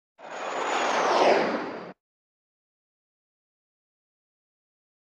Synth Spaceship By 2; Action Panning From Right To Left. Longer And Lower Pitched Than FX 70.